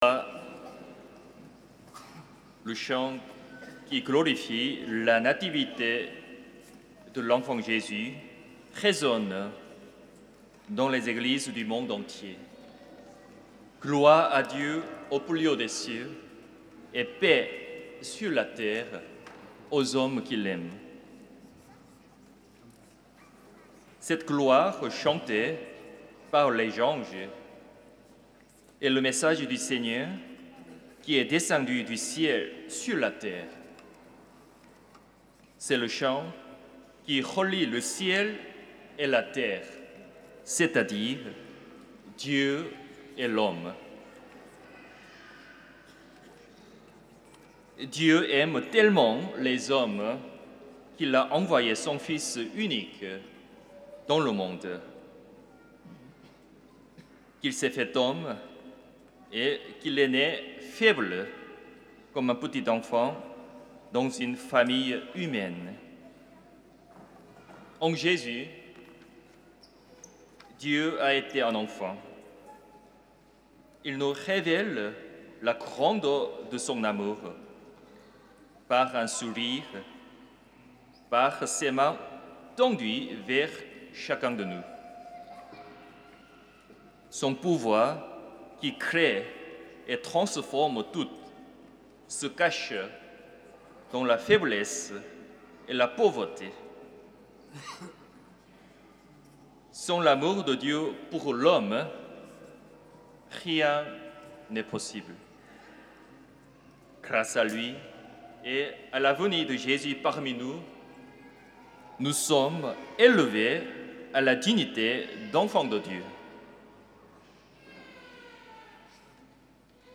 Homélie
Fête de la Sainte Famille